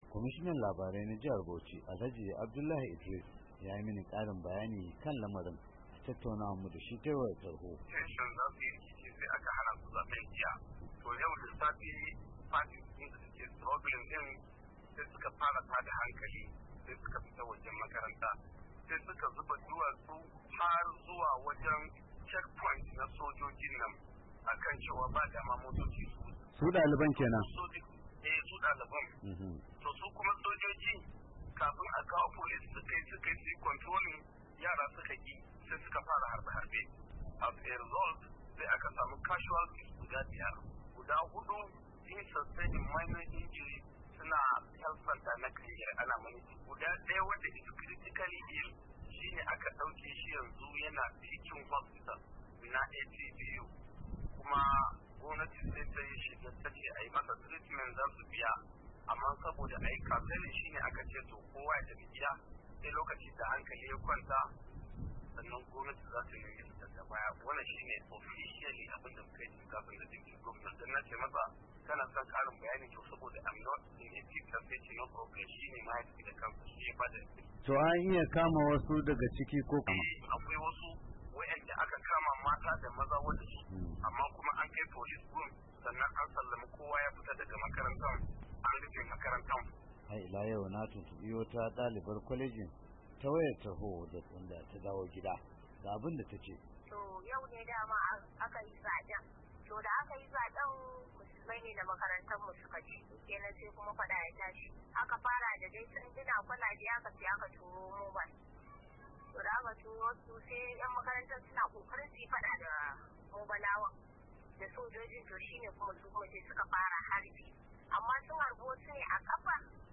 Kwamishinan yada labaran jihar Bauchi